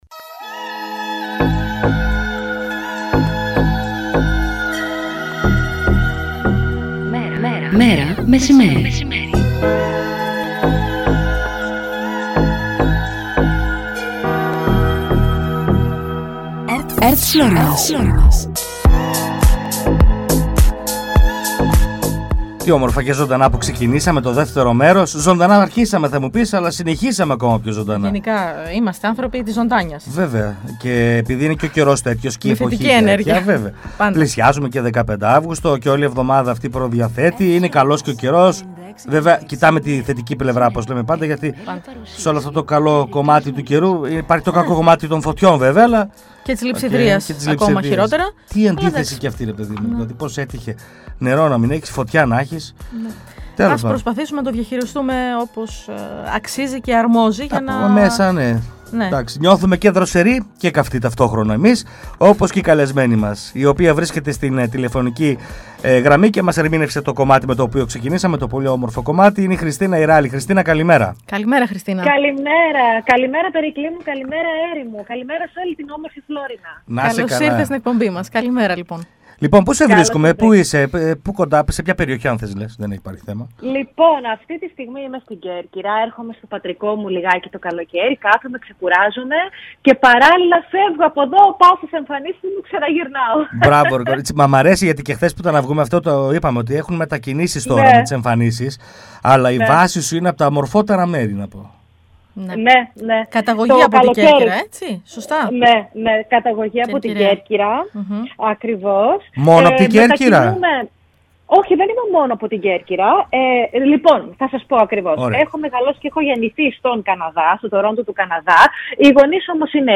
«Μέρα μεσημέρι» Καθημερινό ραδιοφωνικό μαγκαζίνο που ασχολείται με ρεπορτάζ της καθημερινότητας, παρουσιάσεις νέων δισκογραφικών δουλειών, συνεντεύξεις καλλιτεχνών και ανάδειξη νέων ανθρώπων της τέχνης και του πολιτισμού.